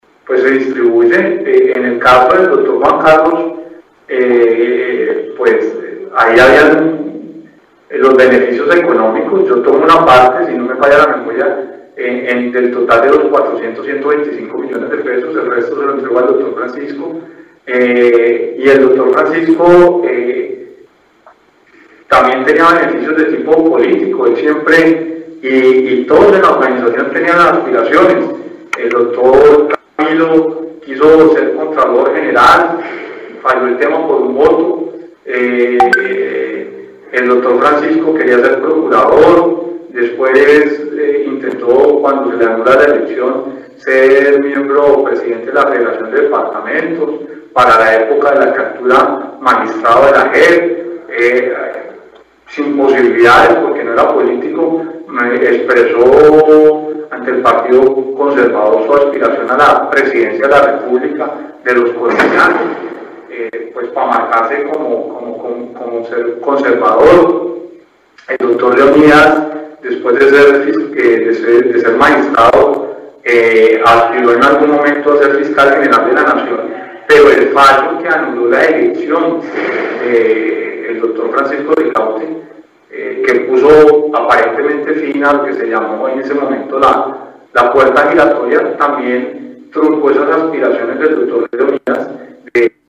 Gustavo Moreno, exfiscal anticorrupción, entregó su testimonio durante el juicio que se adelanta contra el magistrado Francisco Ricaurte por el llamado Cartel de la Toga.